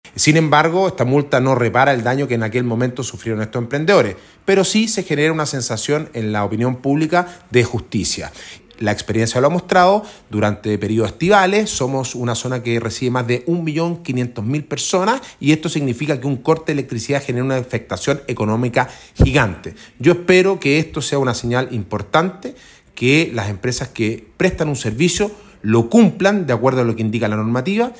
El alcalde de Pucón, Sebastián Álvarez, dijo que la sanción no repara el daño que sufrieron comerciantes de la comuna.